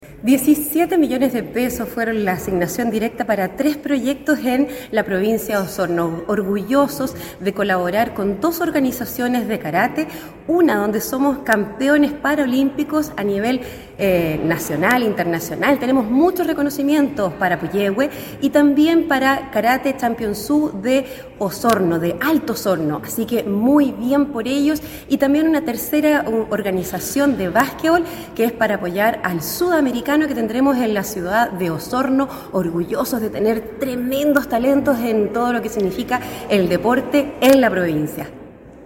La consejera regional de Osorno, Andrea Iturriaga, destacó la importancia de esta inversión, señalando que 17 millones de pesos fueron la asignación directa para tres proyectos a nivel provincial, entre los que destaca el deporte paralímpico.